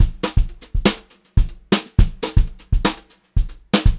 120HRBEAT1-R.wav